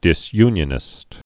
(dĭs-ynyə-nĭst)